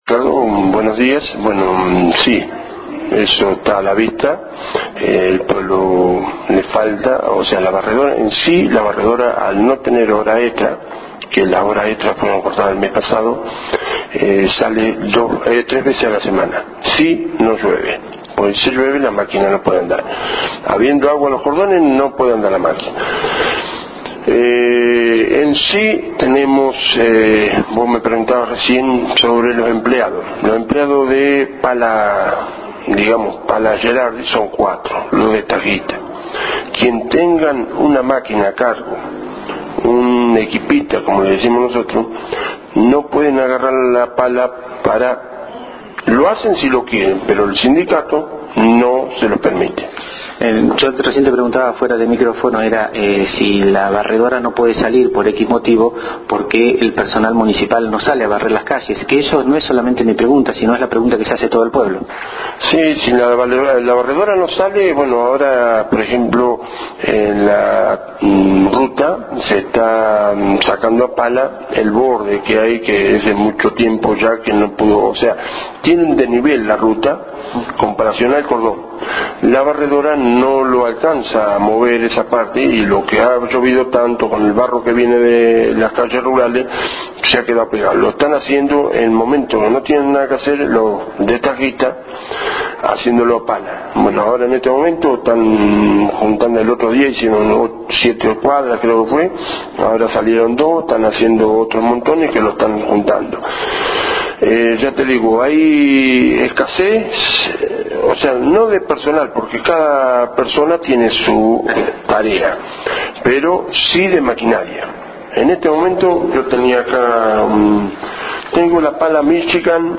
En una charla mantenida con el Delegado Municipal Alberto Taarning, hablamos del mal estado del pueblo. Sucio, roto, sus calles de tierra muy poceadas, son el reflejo y los motivos de un disconformismo generalizado de los vecinos.